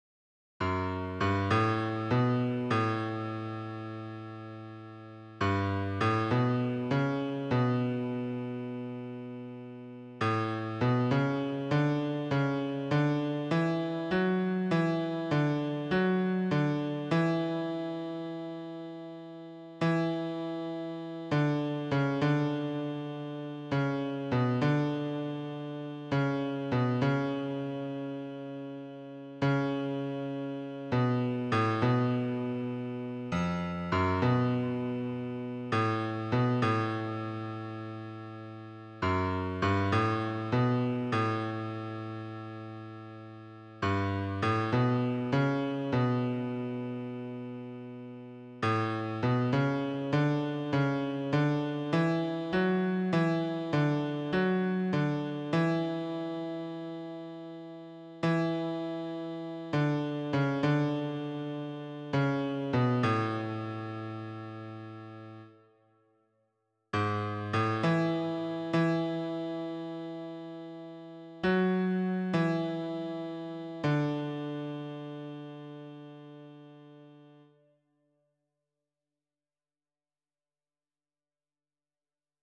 esse_seu_olhar_-_baixo[50289].mp3